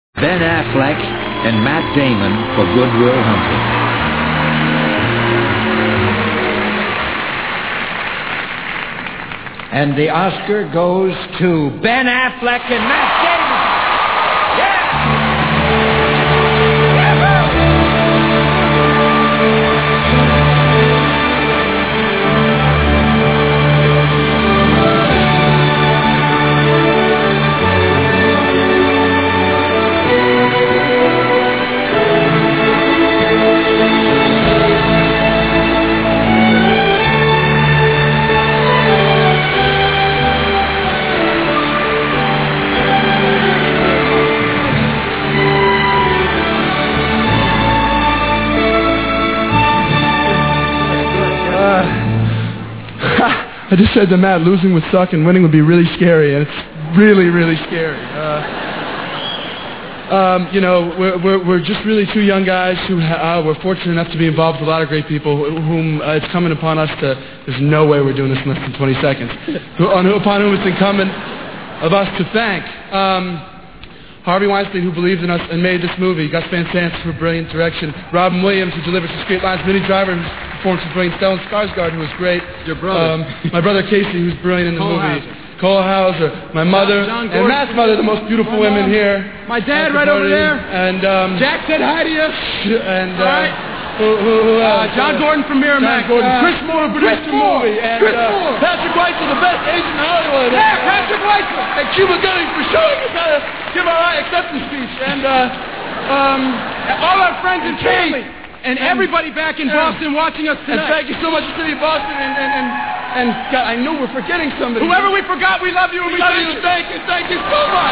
Here's Ben Affleck's and Matt Damon's acceptance speech for the Academy Award ('Original Screenplay'):
benmattspeech.mp3